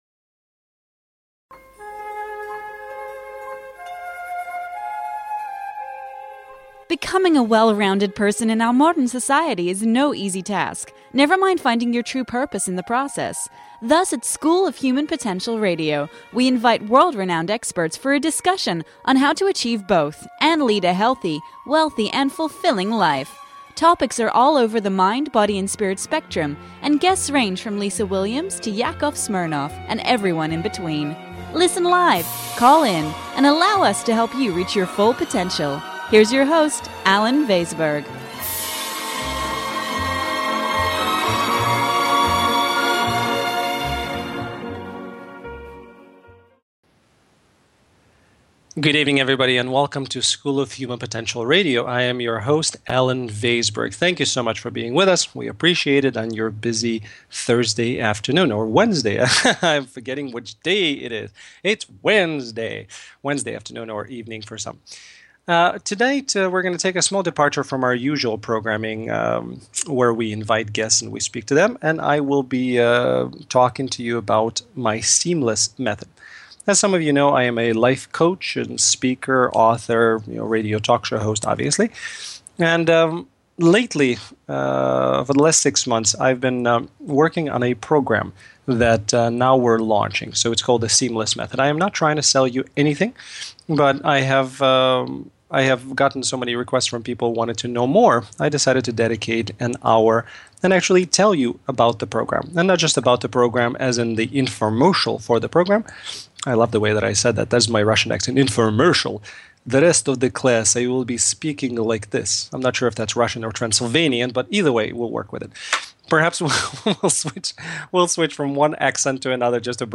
Talk Show Episode, Audio Podcast, School of Human Potential and The Seemless Method on , show guests , about Human Potential,Seemless Method, categorized as Kids & Family,Philosophy,Psychology,Mental Health,Self Help,Spiritual